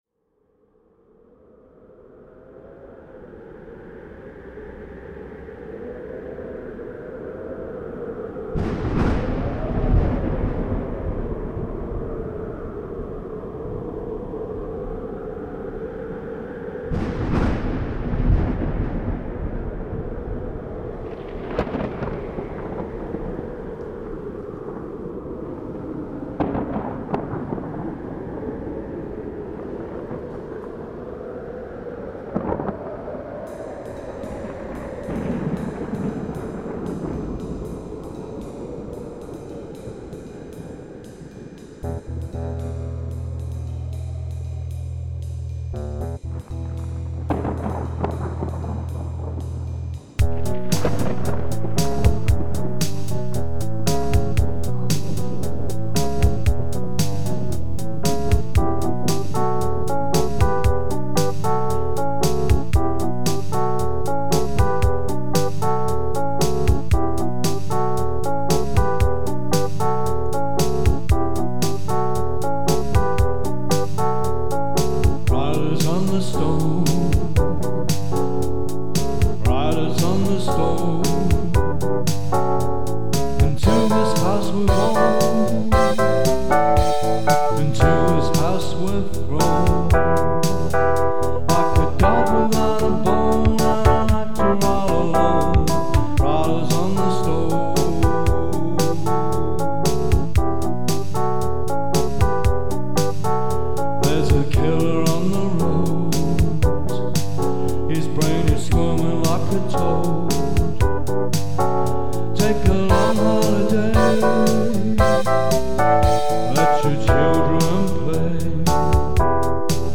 5:32/115bpm